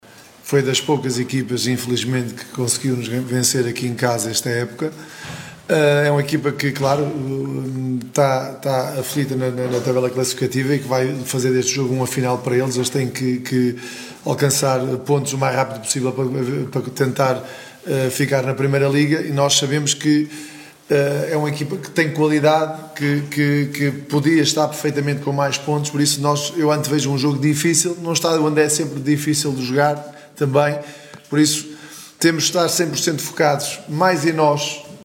Cesar Peixoto, treinador da equipa barcelense, perspectiva um jogo dificil.